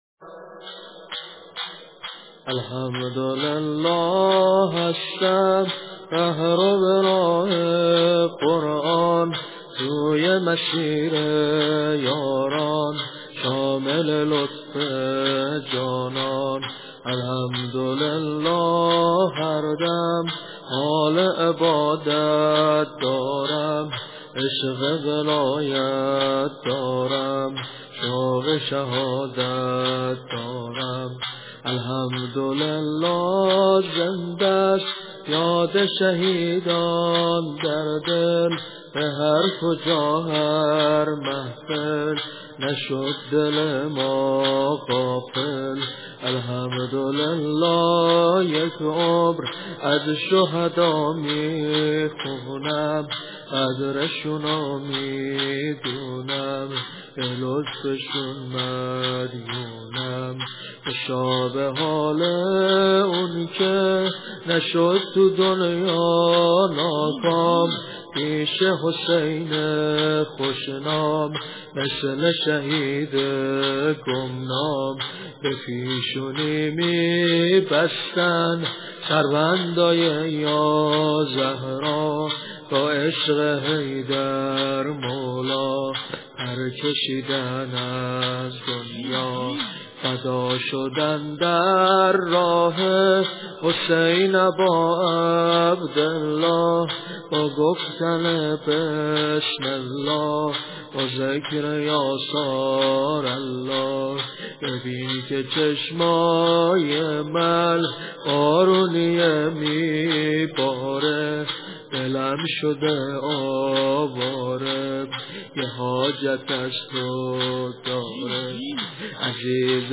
*** سبک ناحلة اللجسم ***